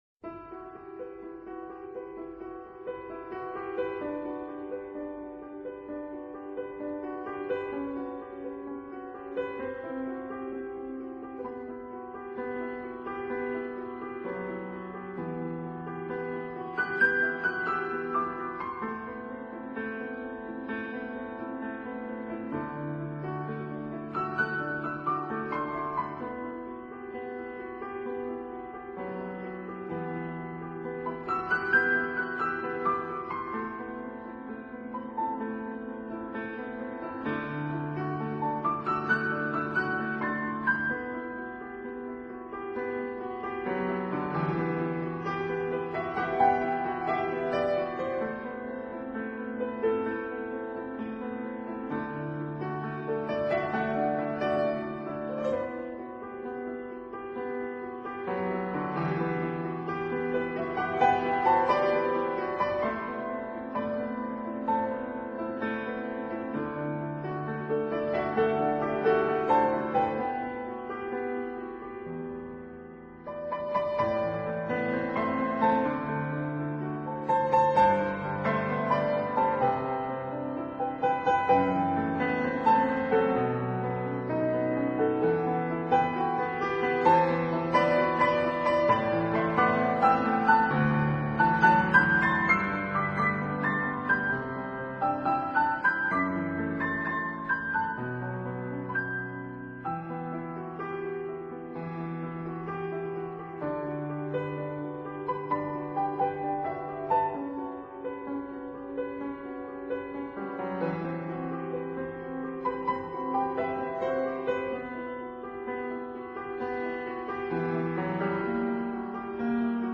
【新世纪钢琴】
在27首单曲中大部分是钢琴独奏。